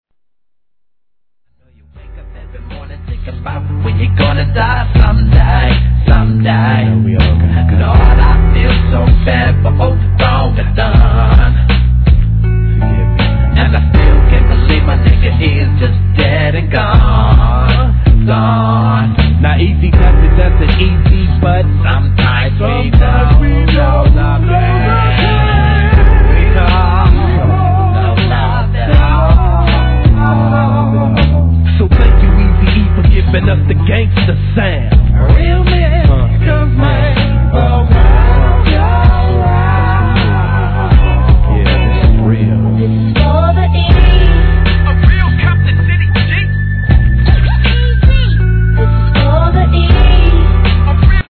G-RAP/WEST COAST/SOUTH
切ない哀愁漂うベースとピアノに思わず。。。